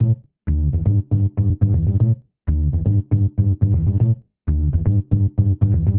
The user wanted only one small piece of the whole bass loop, so a small piece was split off.